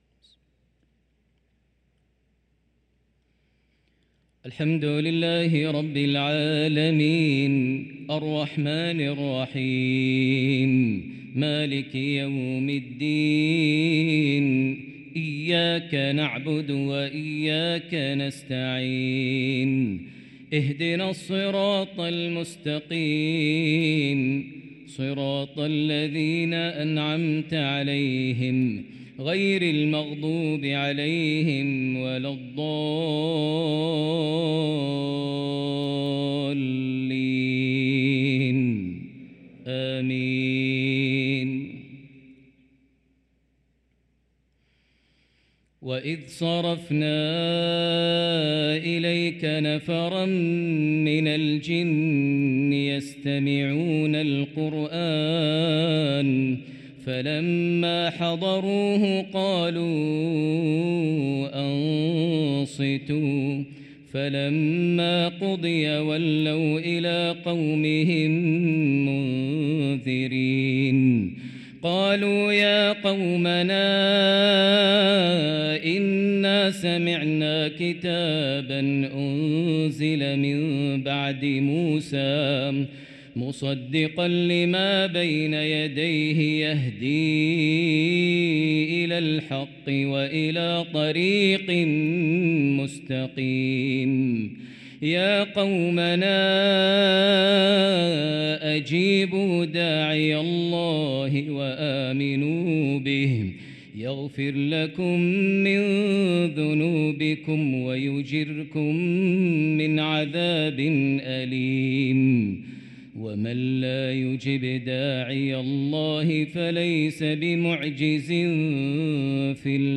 صلاة المغرب للقارئ ماهر المعيقلي 21 صفر 1445 هـ
تِلَاوَات الْحَرَمَيْن .